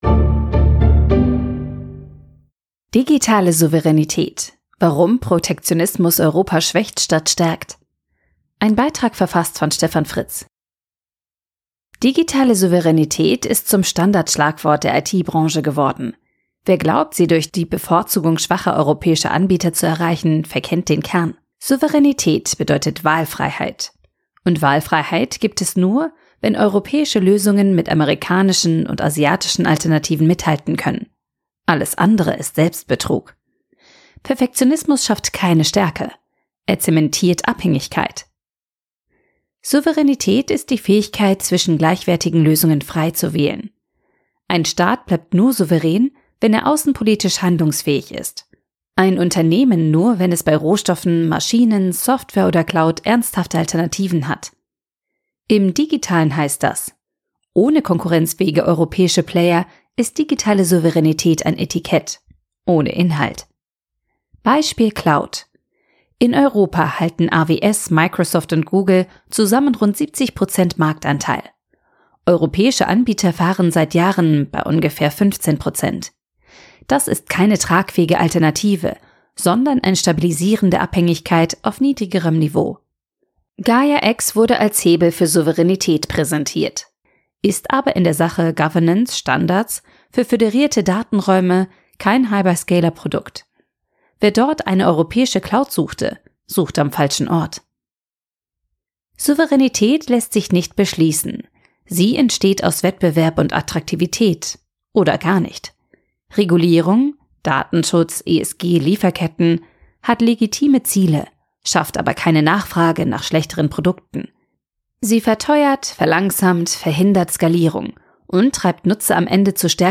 zum Überall-Hören - vorgelesen von narando.